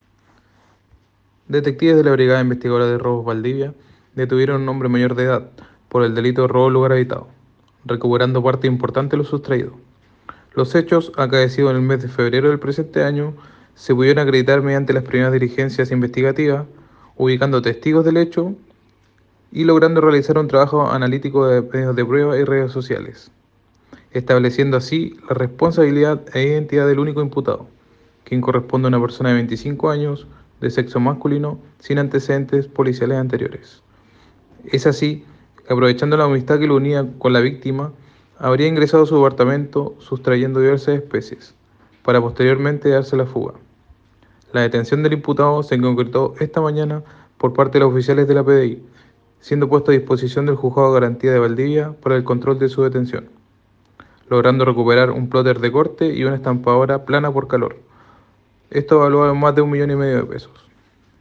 Cuña